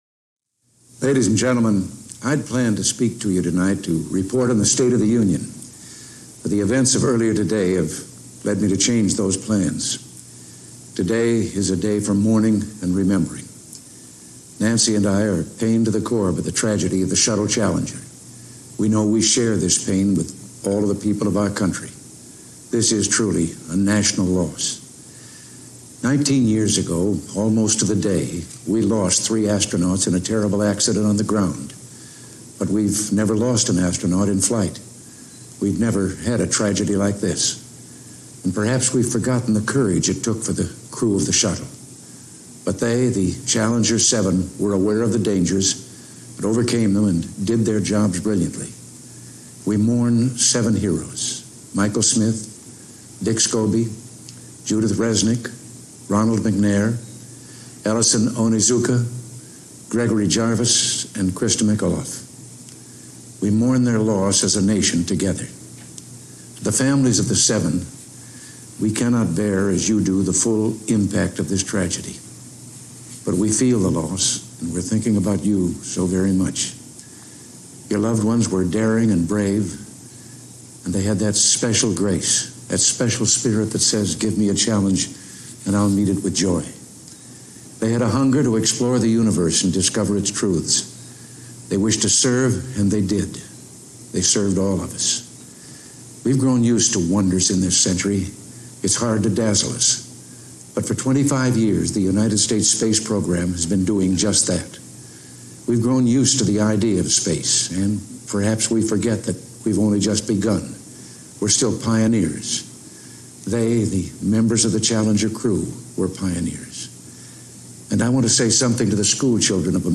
delivered 28 January 1986, Oval Officer, White House, Washington, D.C.
Audio Note: Audio Remastered 6/27/24. AR-XE = American Rhetoric Extreme Enhancement